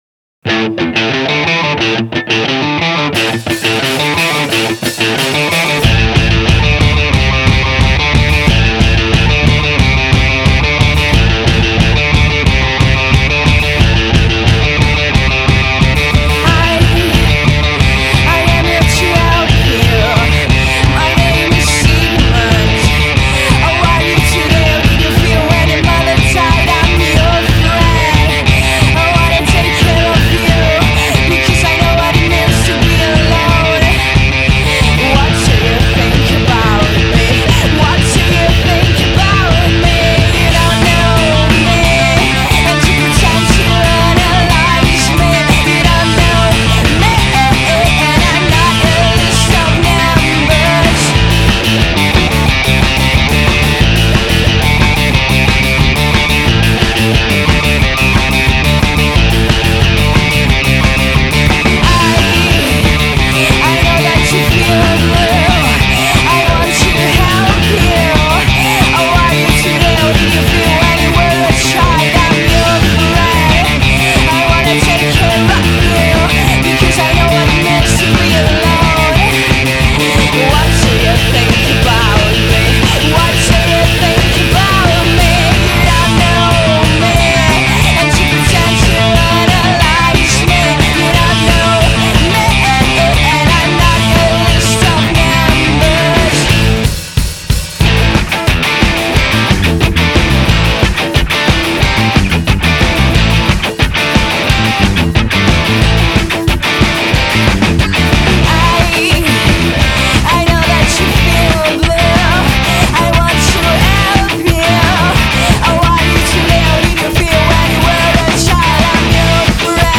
three young kids who play sharp and dry rock ‘n’ roll